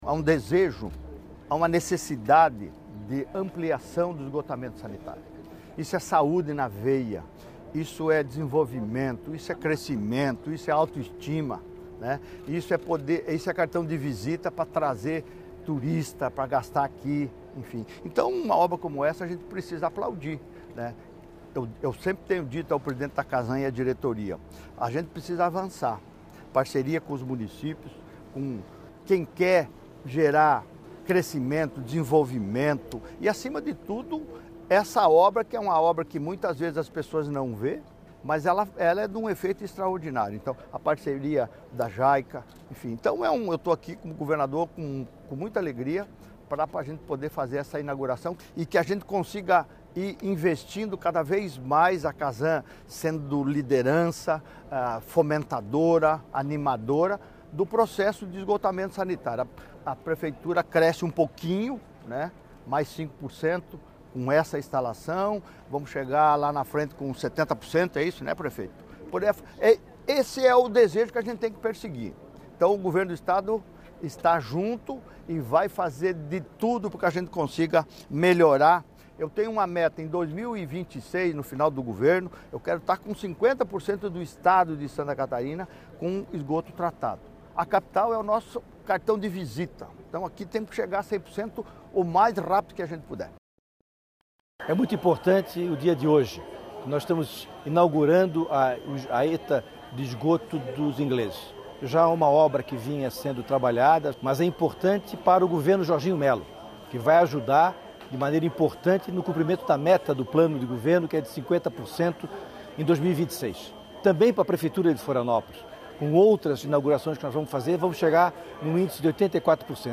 Em evento realizado na tarde desta segunda-feira, 27, o governador Jorginho Mello inaugurou a Estação de Tratamento de Esgoto dos Ingleses, uma obra da CASAN (Companhia Catarinense de Águas e Saneamento) que vai beneficiar cerca de 42 mil moradores e aumentar em 5% a cobertura de esgoto na capital.
O governador destacou que a entrega da obra contribui para o cumprimento do compromisso de avançar no esgotamento sanitário do estado:
ACN-Sonoras-Inauguracao-ETE-Norte-da-Ilha.mp3